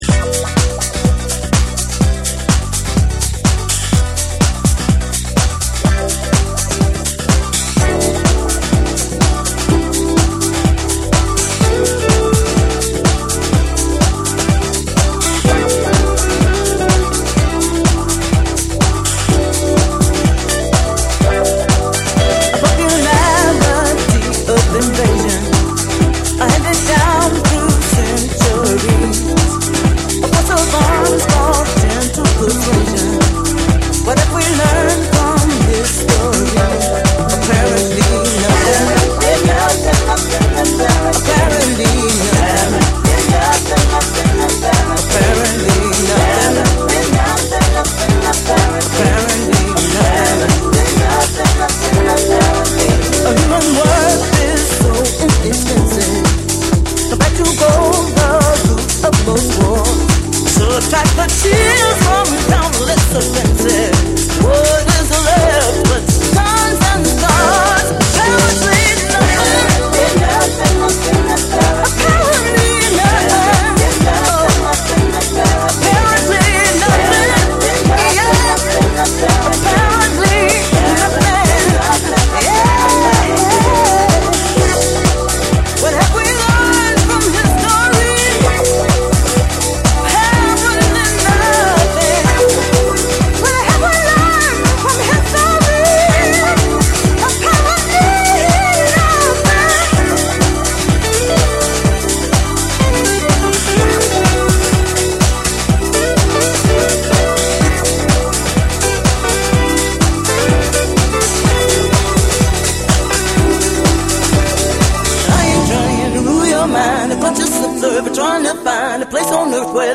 TECHNO & HOUSE / BREAKBEATS / SOUL & FUNK & JAZZ & etc